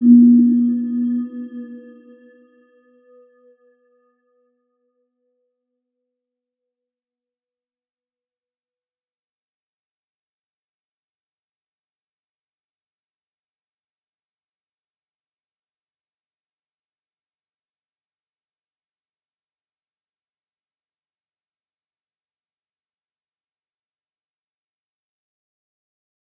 Round-Bell-B3-p.wav